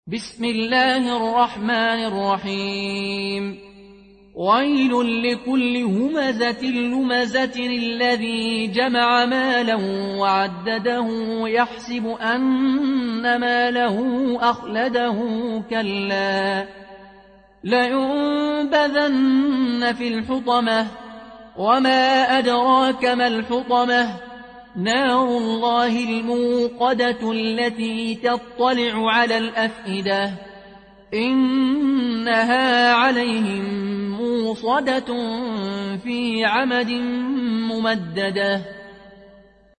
قالون عن نافع